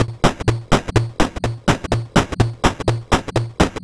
Ghetto Tech 01.wav